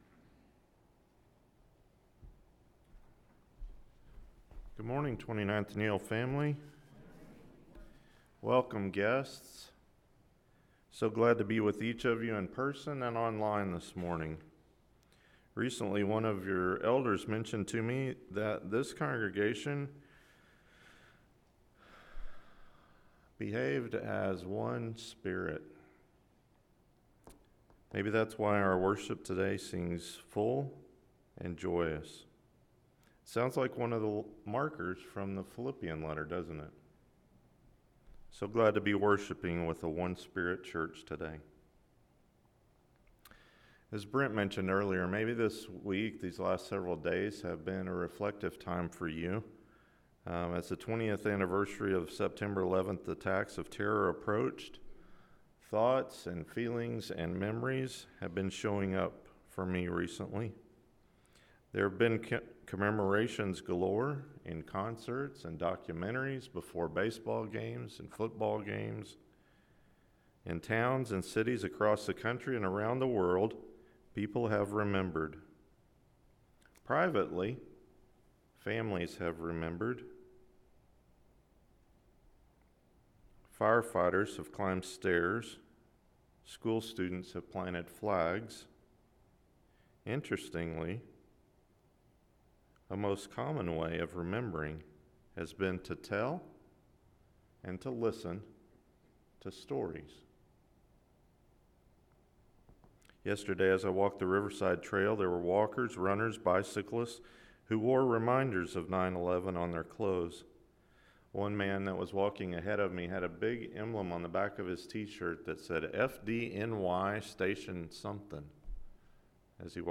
Kingdom Stories: Jesus’ Parables – Mark 1:1-20 – Sermon